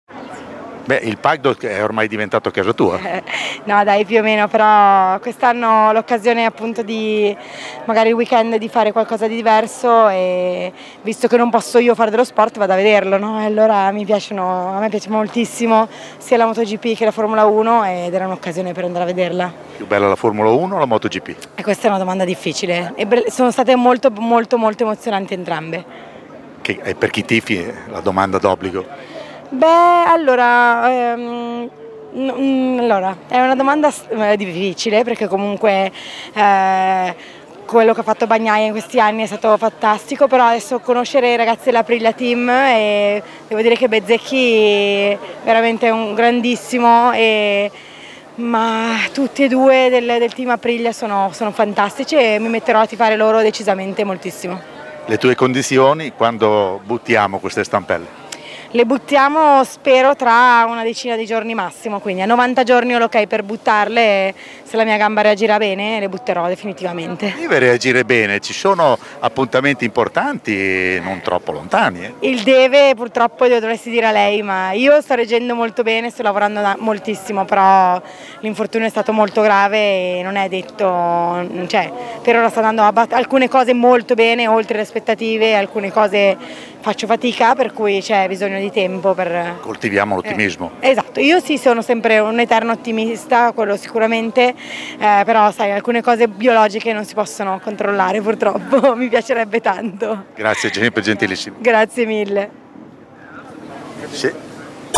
Federica Brignone, 3 ori Olimpici e 5 Iridati, intervistata